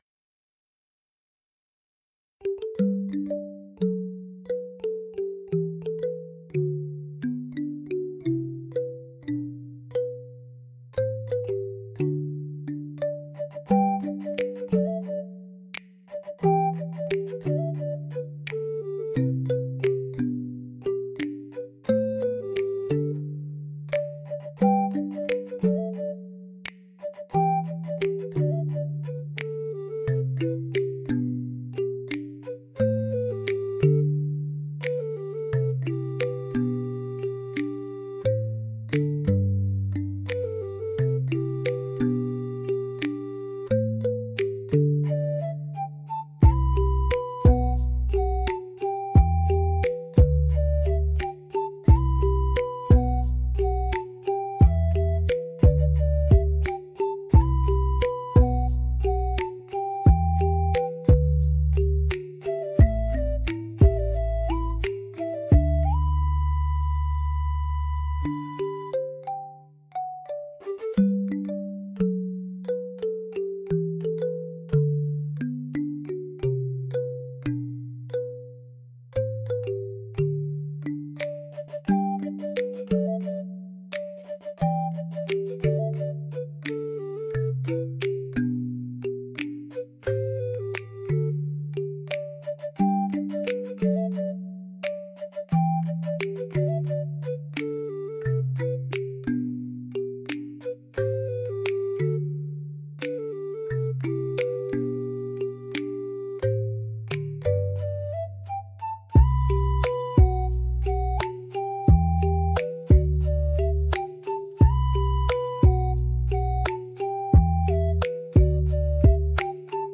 POPS
ほのぼの , ポップス , リコーダー , 日常 , 春 , 朝 , 穏やか , 静か